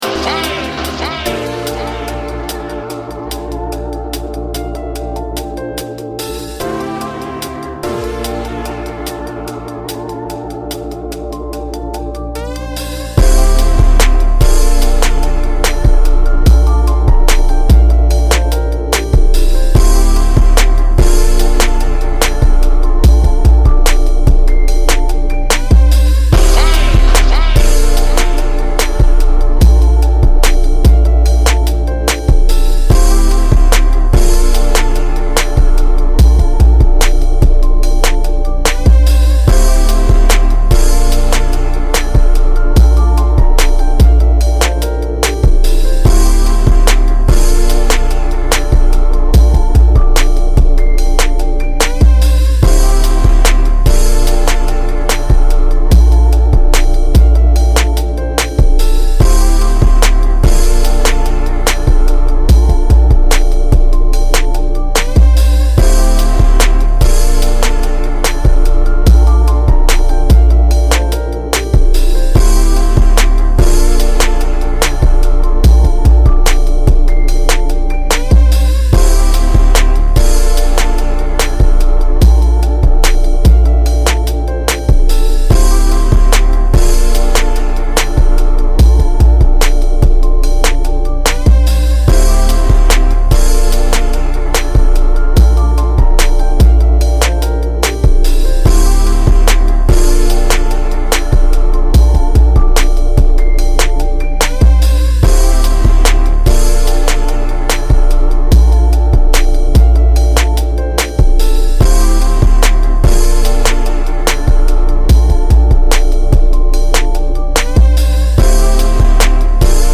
Additional Keys